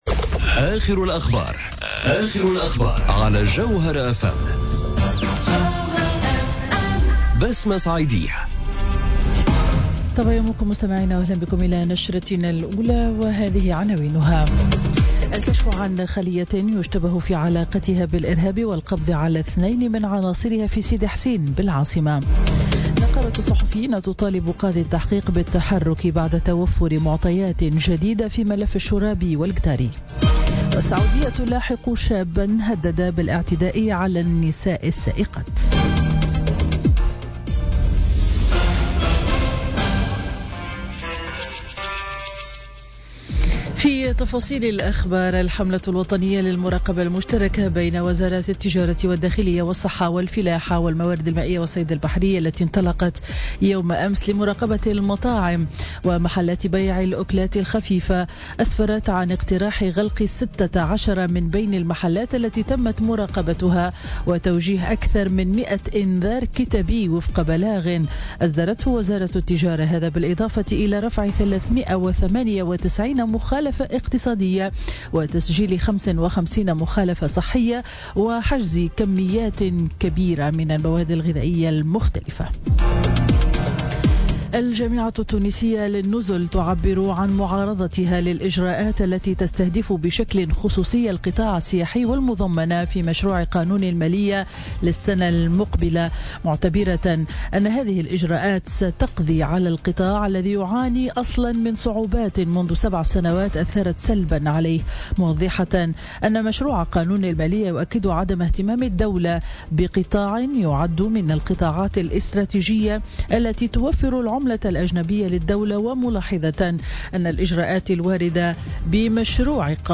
Journal Info 07h00 du vendredi 29 septembre 2017